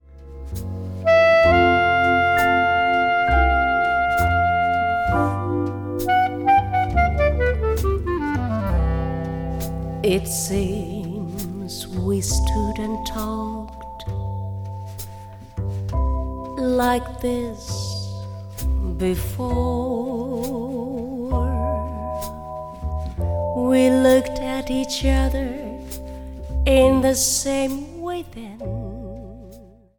Vibraphone
Piano
Bass
Drums